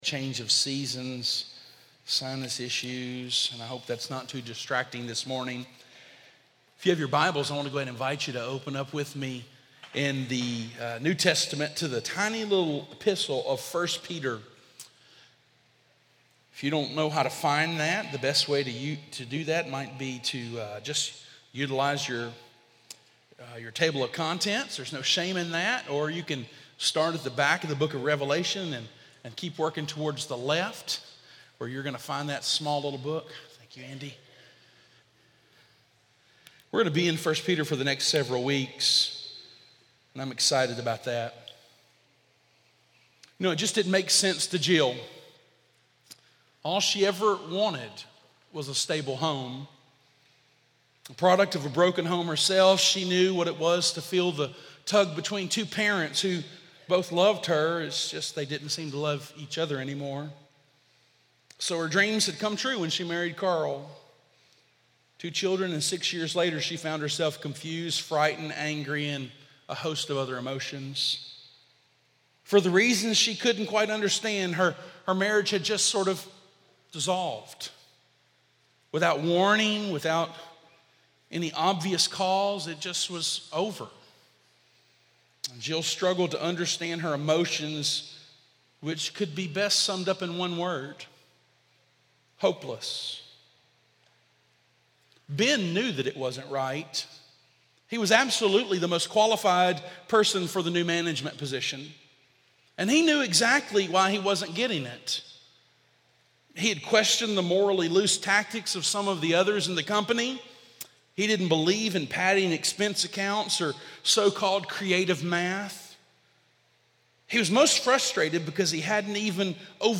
Hopeless Wanderers: The Cry of a Wounded Heart — Crossroads Baptist Church of Elizabethtown